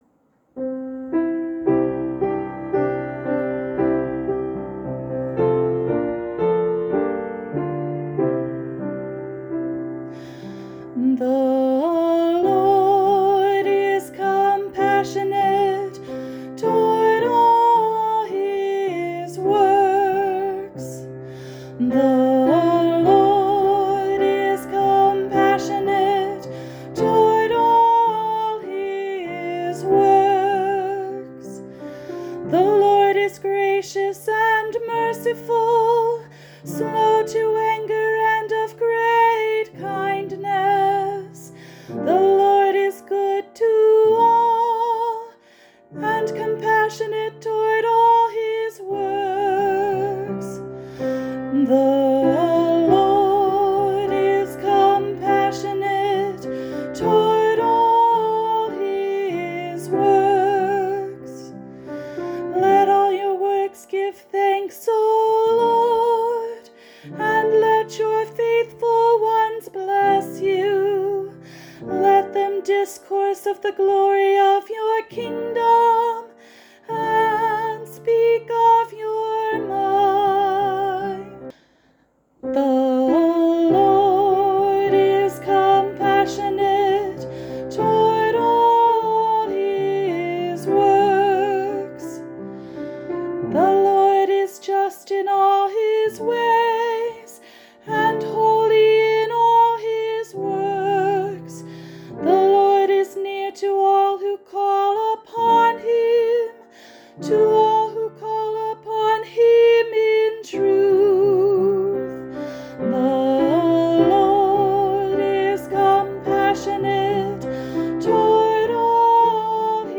Canlas Psalm Click the following for recordings:  a cappella |